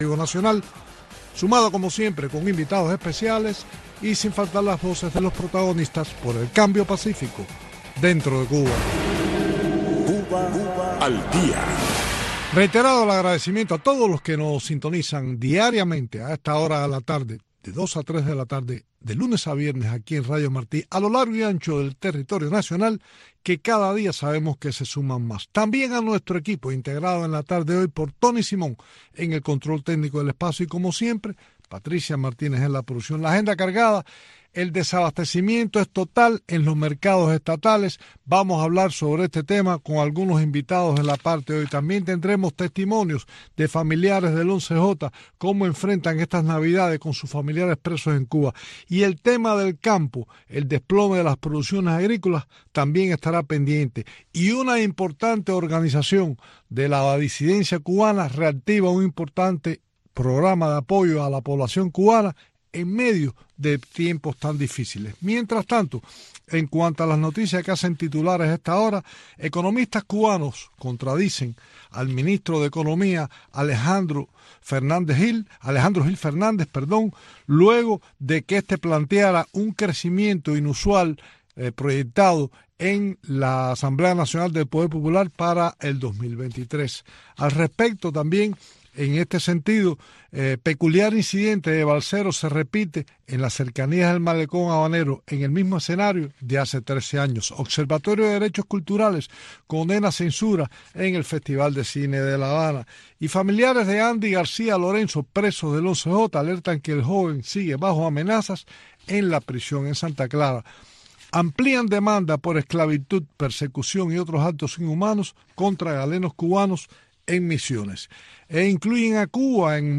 espacio informativo en vivo